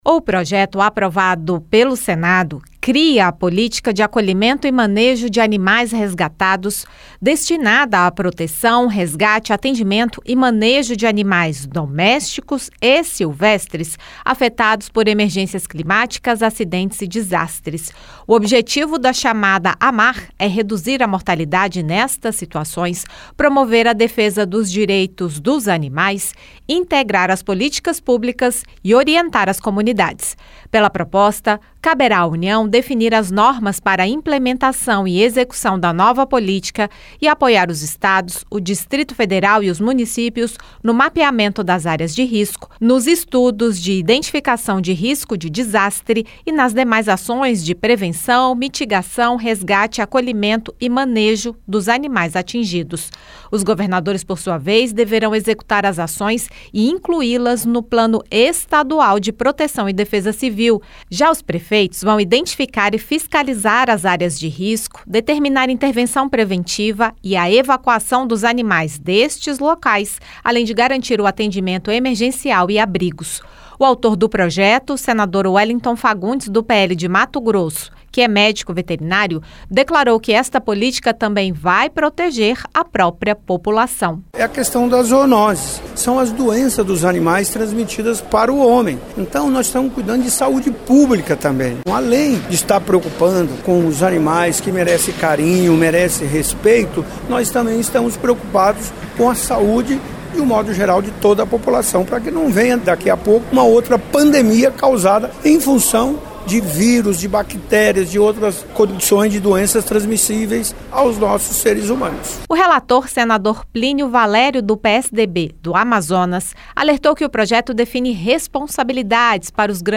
Já o relator, senador Plínio Valério (PSDB-AM), destacou que a proposta também inclui os grandes empreendedores, como barragens e mineradoras, que deverão atuar na prevenção e até na oferta de abrigo para os bichos em situação de desastre.